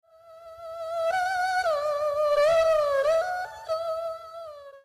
ERHU: UN VIOLÍN
El erhu, más conocido como el violín chino de dos cuerdas, es un instrumento tradicional, con un sonido particular y único.
Su arco está hecho con crin de caballo, las dos cuerdas son de acero y la caja de resonancia está cubierta con piel de serpiente - generalmente pitón. Todo ello produce un sonido muy especial.
erhu.mp3